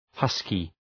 Προφορά
{‘hʌskı}